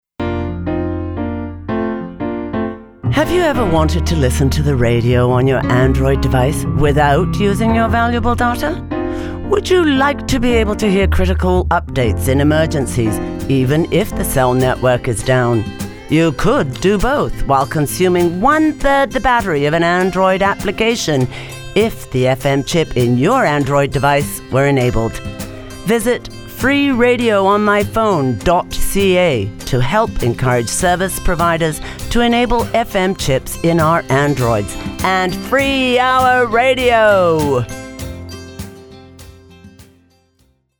Type: PSA